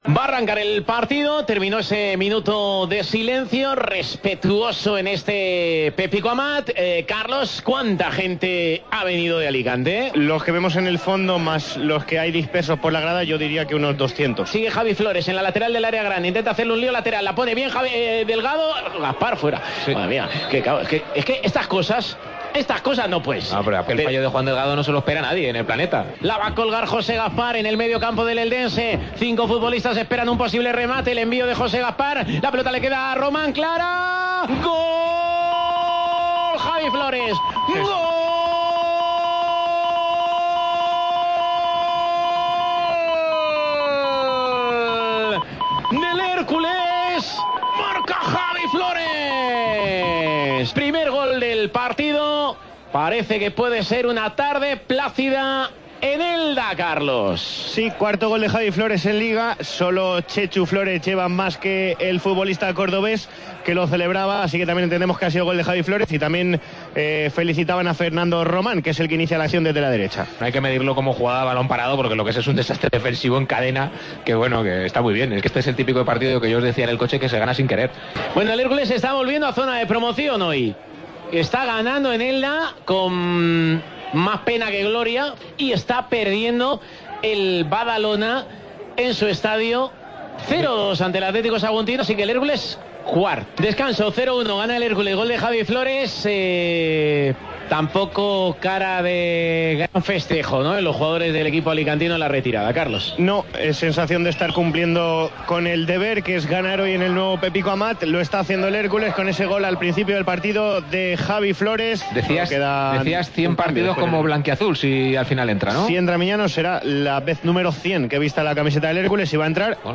Escucha los sonidos más destacados en Tiempo de Juego Alicante de la victoria del Hércules en el Nuevo Pepico Amat.